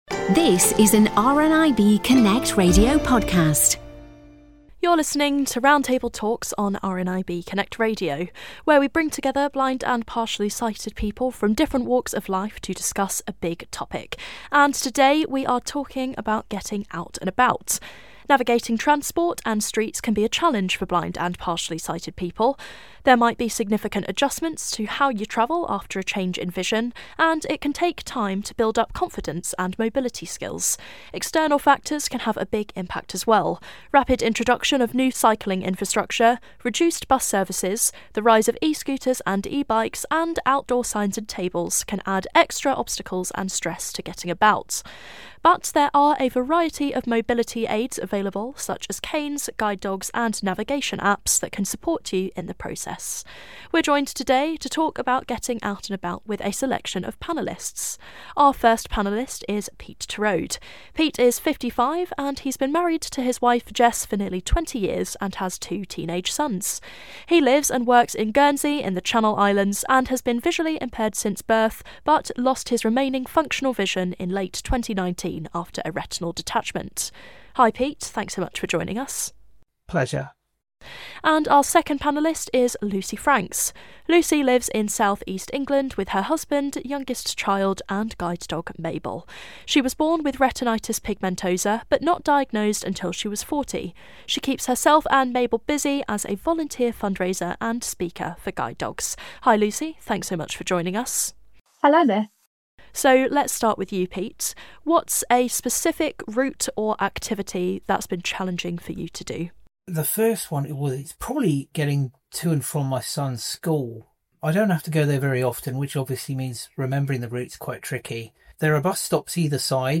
Getting Out And About - Roundtable